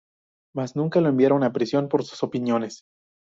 Pronunciado como (IPA) /pɾiˈsjon/